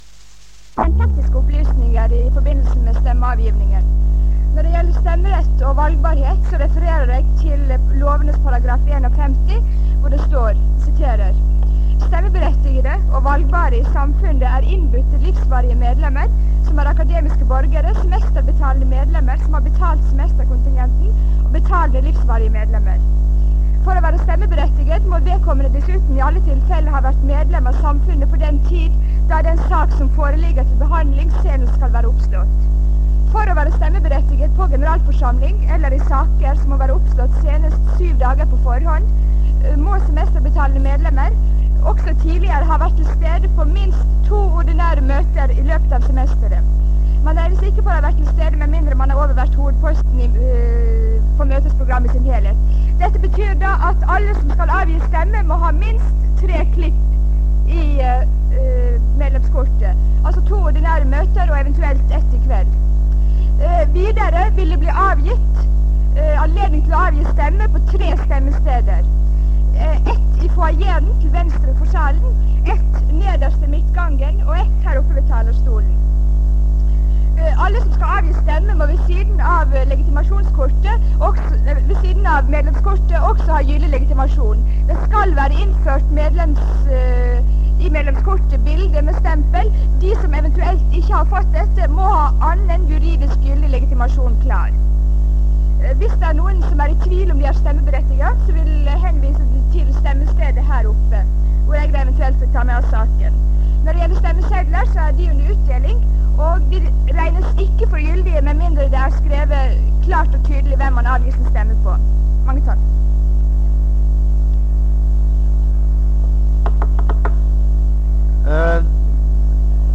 Det Norske Studentersamfund, Generalforsamling, 26.11.1966
Generalforsamling